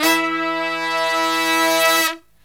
LONG HIT07-L.wav